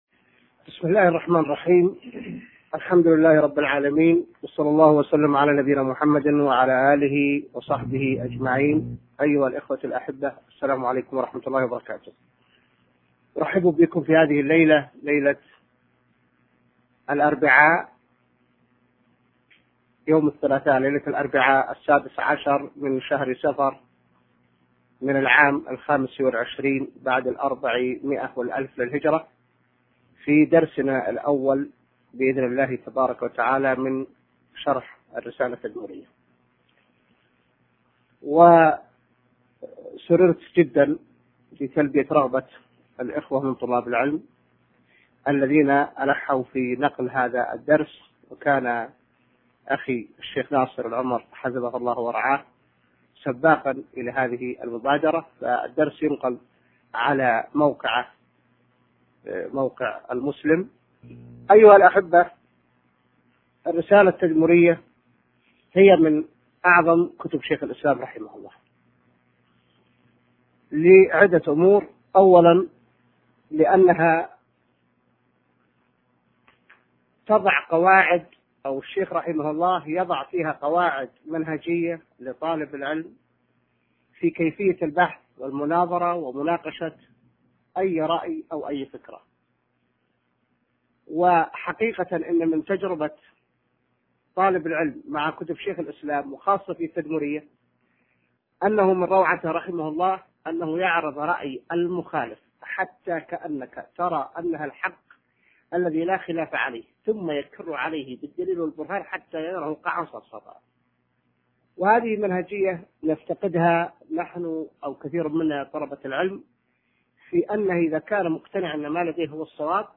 الدرس الأول في شرح الرسالة التدمرية | موقع المسلم